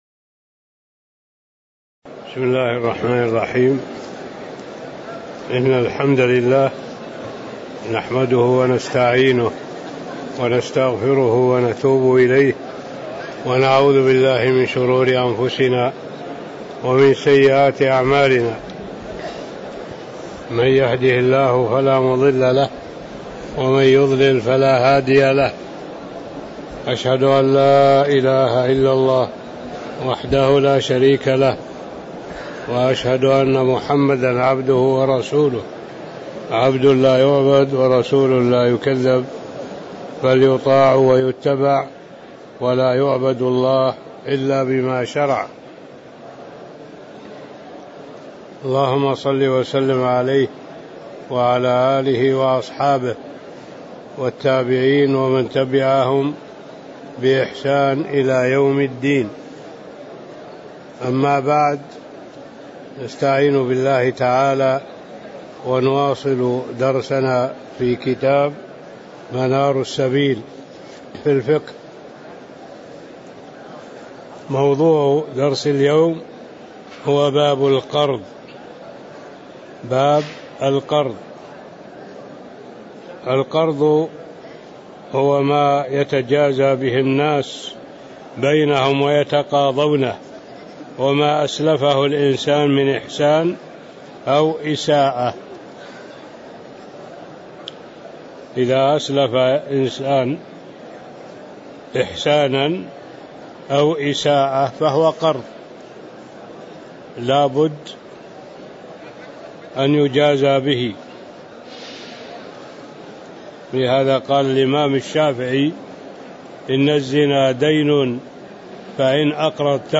تاريخ النشر ١٢ محرم ١٤٣٧ هـ المكان: المسجد النبوي الشيخ